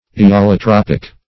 aeolotropic \aeolotropic\, AEolotropic \[AE]`o*lo*trop"ic\, a.